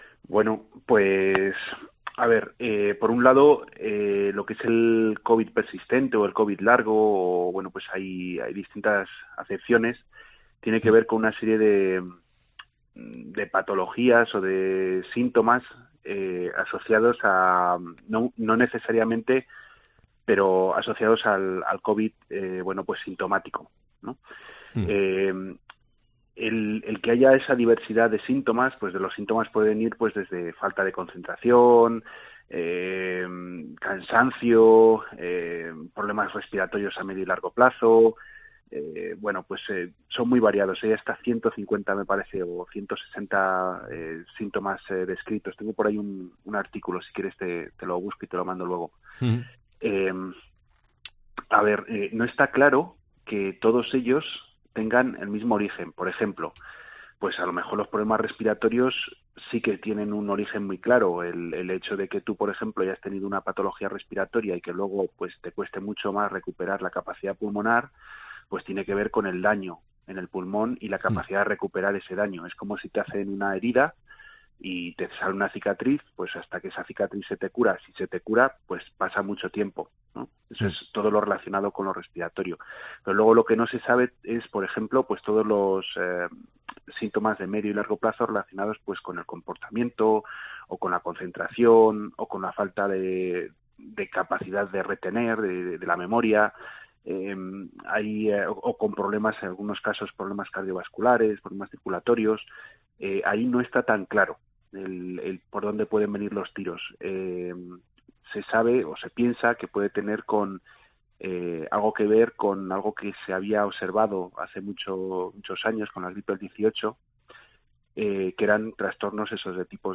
Covid persistente en la voz de un experto